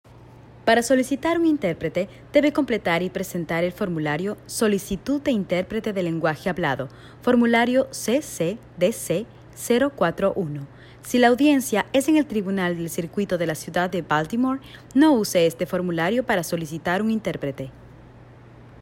female | South American | Standard | adult